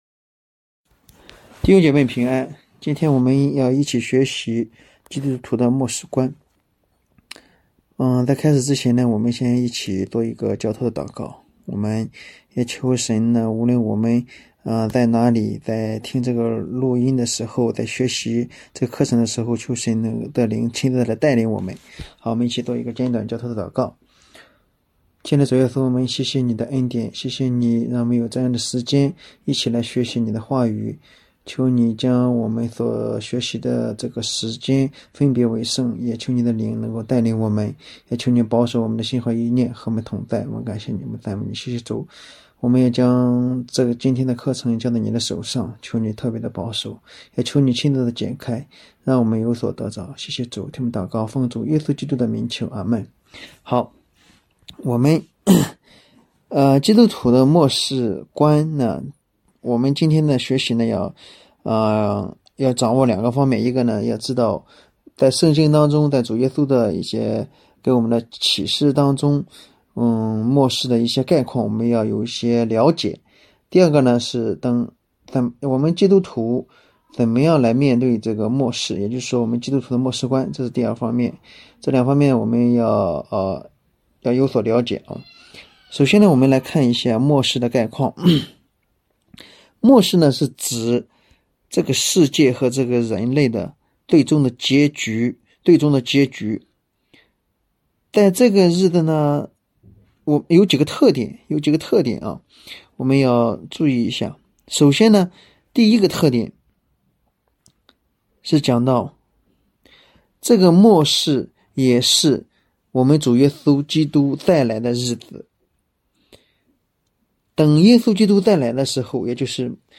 慕道班课程（六）